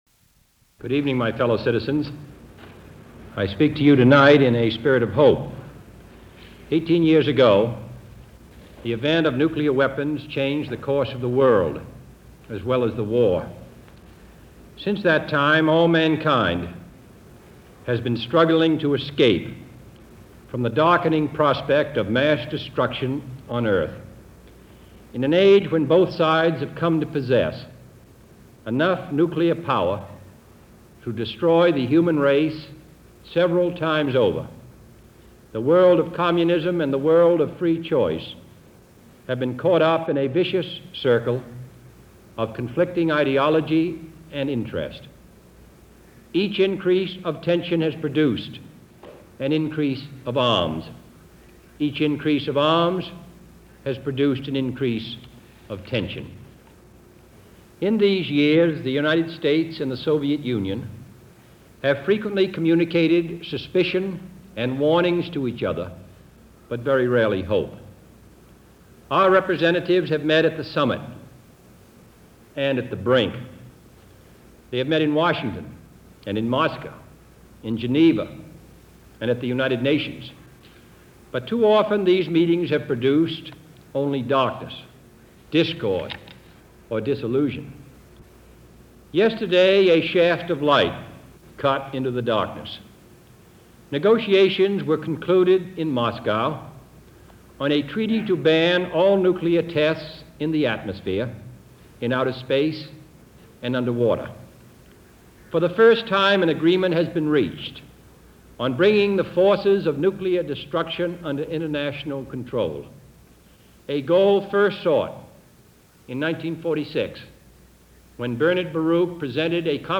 President Kennedy: 'A Shaft Of Light Cut Into The Darkness' - Address On Nuclear Test Ban - July 26, 1963 - Combined networks.
President John F. Kennedy Address to Nation on Nuclear Test Ban